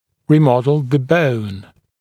[ˌriː’mɔdl ðə bəun][ˌри:’модл зэ боун]ремоделировать кость